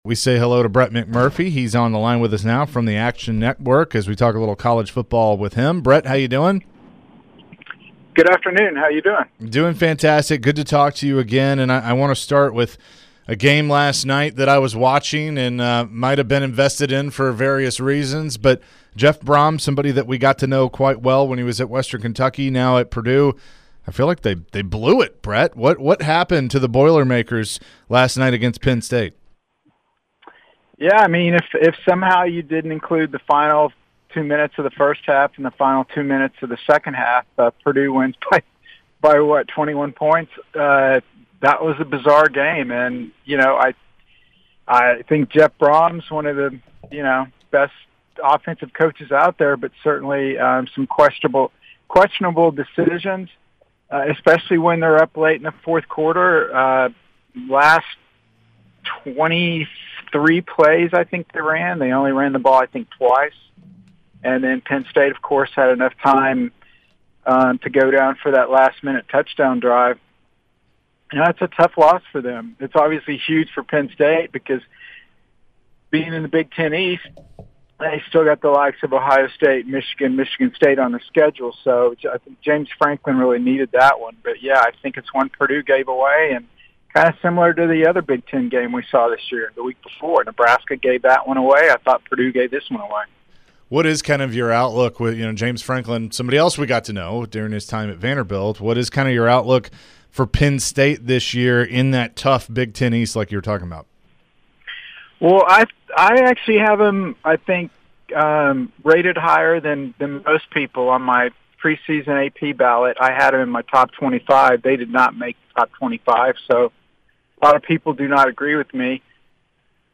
Brett McMurphy interview (9-2-22)